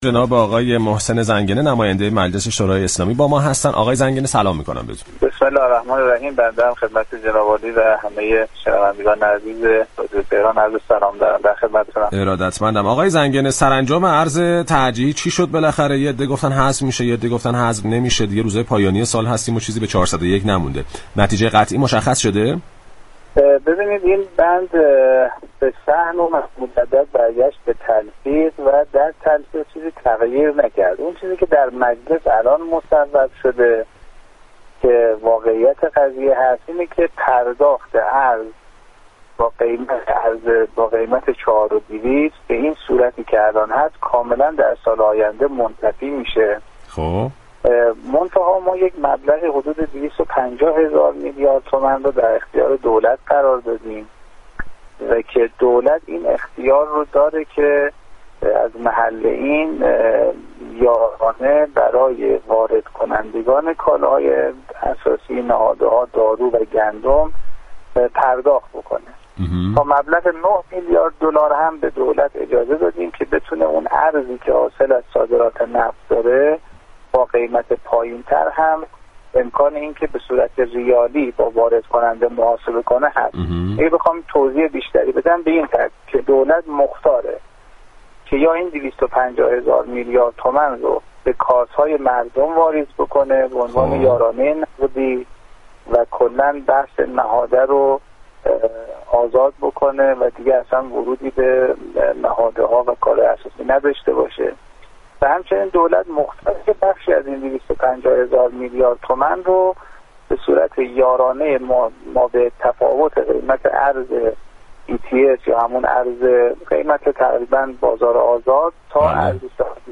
به گزارش پایگاه اطلاع رسانی رادیو تهران، محسن زنگنه عضو كمیسیون تلفیق بودجه سال 1401 در گفتگو با بازار تهران رادیو تهران درخصوص سرانجام شیوه تخصیص ارز ترجیحی در بودجه سال آینده گفت: طبق آخرین مصوبه مجلس در سال آینده عملا ارز با قیمت 4200 تومانی نداریم و مجلس مبلغ 250 هزار میلیارد تومان در اختیار دولت قرار داده است تا به اختیار خود به واردكنندگان كالاهای اساسی یارانه اختصاص دهد.